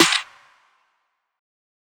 Snares
Skyfall 2 [Snare](1).wav